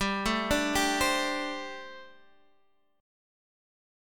Gsus2sus4 chord